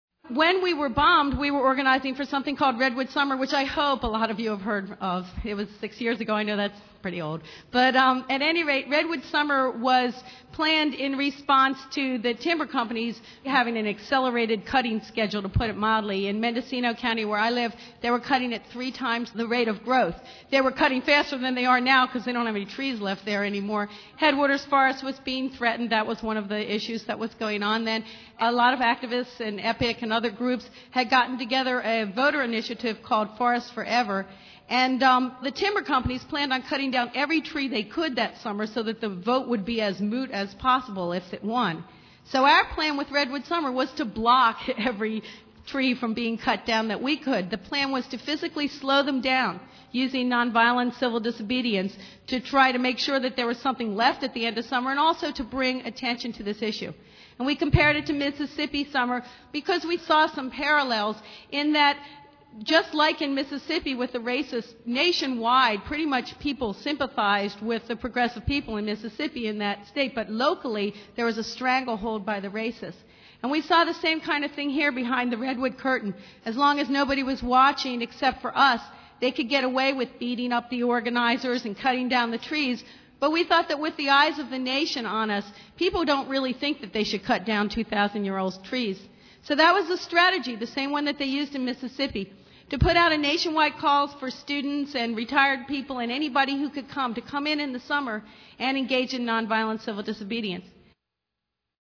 This is a compilation of recordings of Judi speaking at many different times and places, talking about her case and her beliefs on the radio and to interviewers, or speaking to thousands at the 1996 Rally for Headwaters.